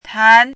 怎么读
tán